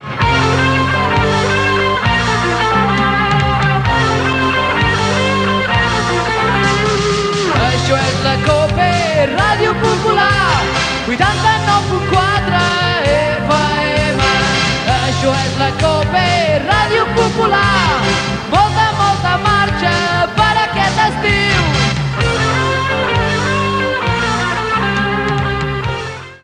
Indicatiu d'estiu de l'emissora